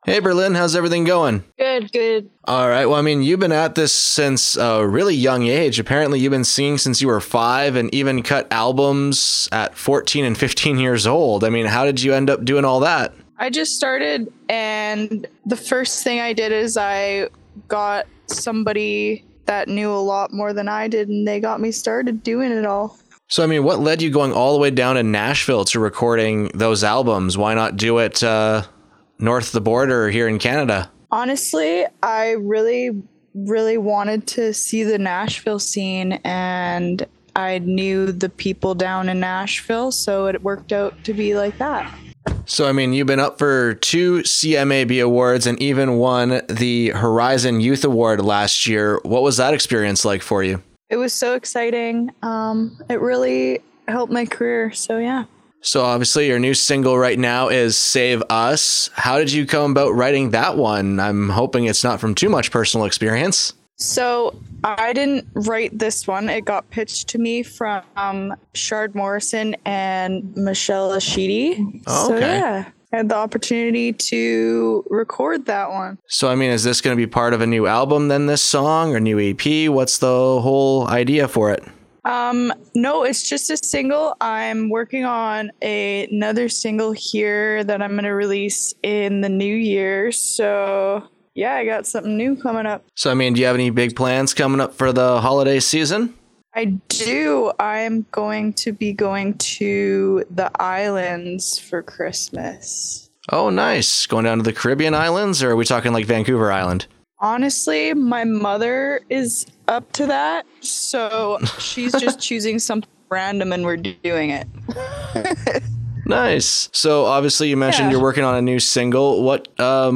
Rising Stars Headliner Interview